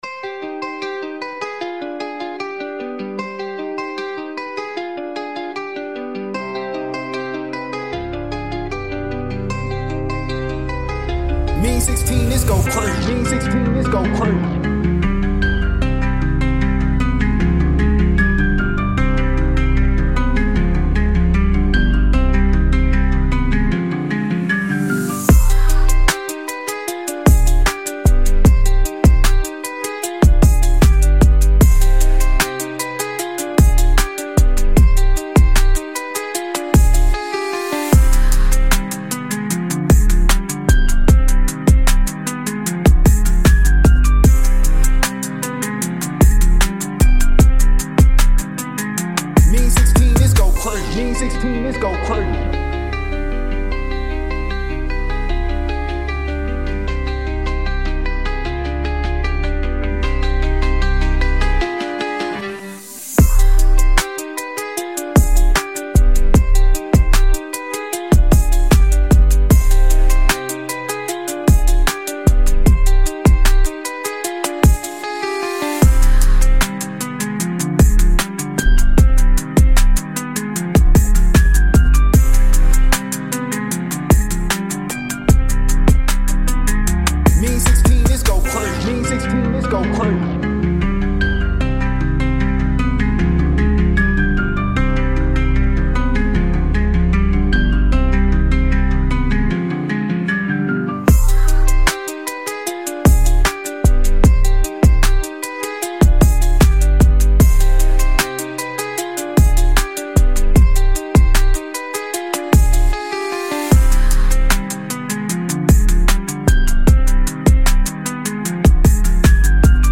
trap beats